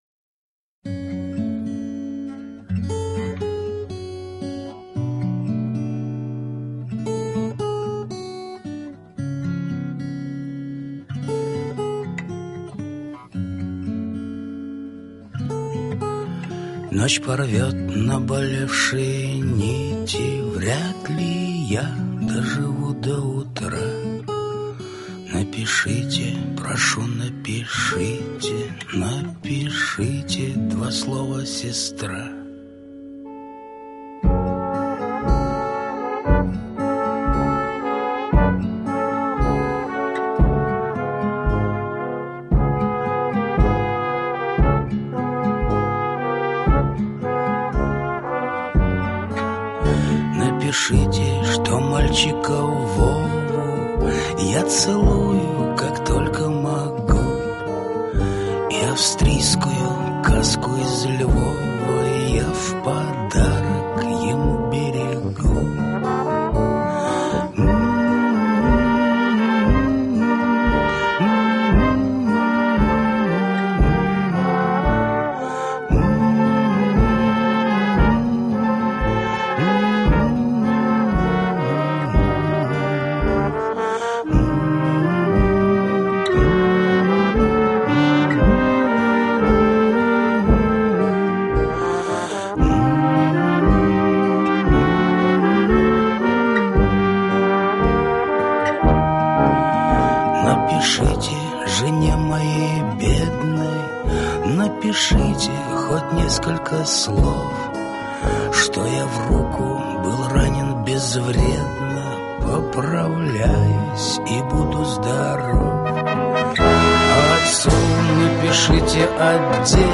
ансамль
песня